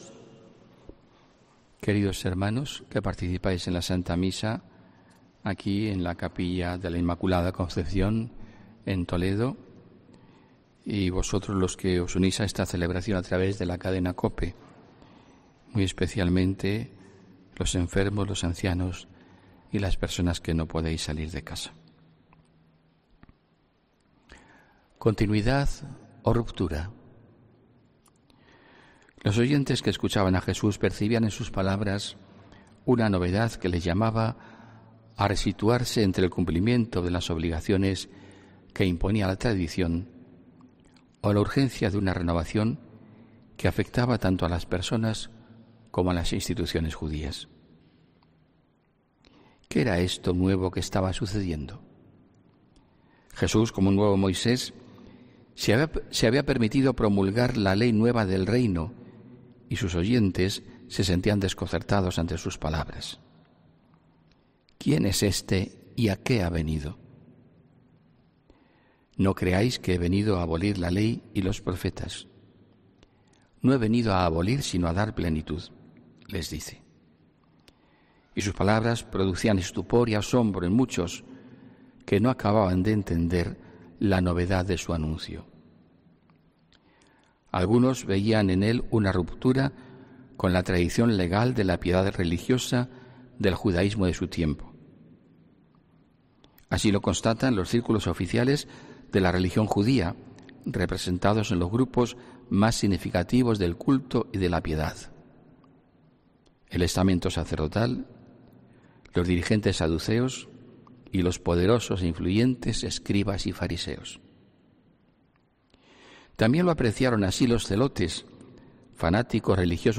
HOMILÍA 16 FEBRERO